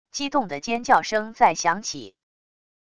激动的尖叫声在响起wav音频